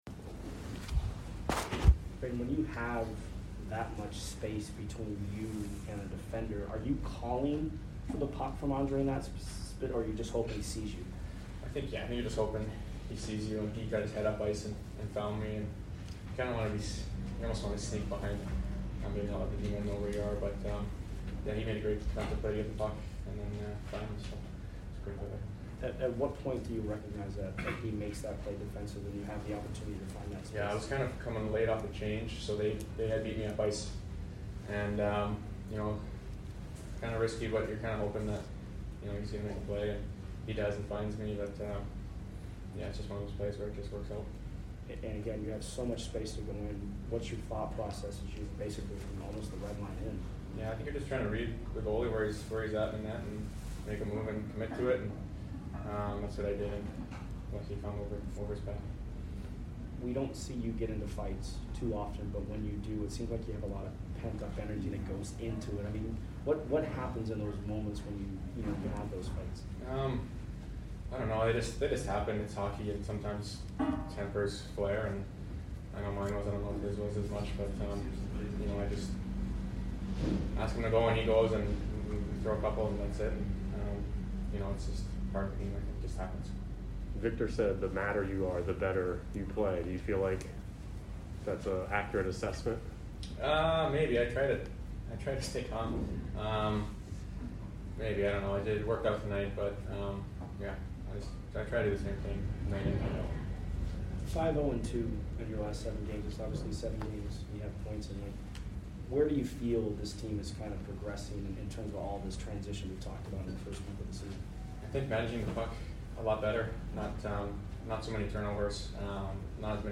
Brayden Point Post Game Vs FLA 11 - 13 - 21